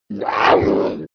Звуки рыси
Гром яростной рысятины